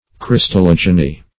Search Result for " crystallogeny" : The Collaborative International Dictionary of English v.0.48: Crystallogeny \Crys`tal*log"e*ny\ (kr?s`tal-l?j"?-n?), n. [Gr. kry`stallos crystal + root of gi`gnesqai to be born.] The science which pertains to the production of crystals.